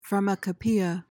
PRONUNCIATION:
(far-muh-kuh-PEE-uh)